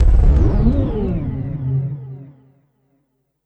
35SFX 01  -L.wav